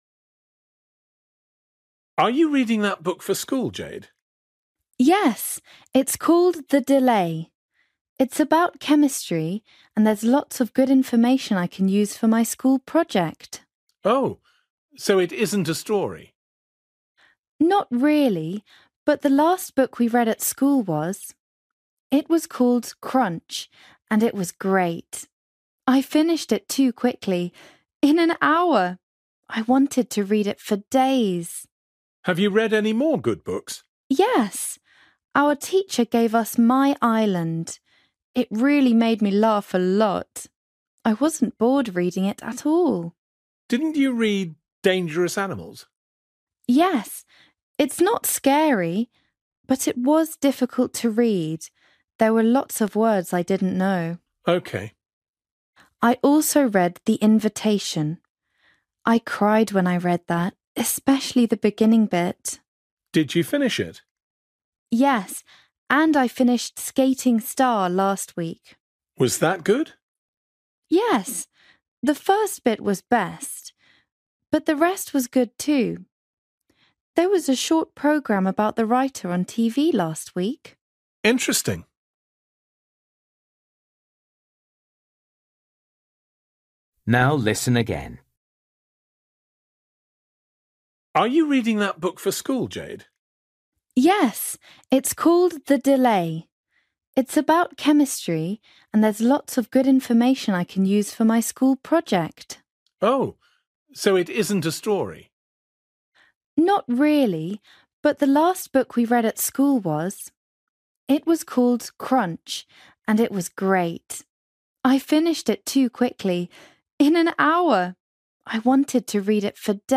You will hear a girl talking to her uncle about the books she has read at school.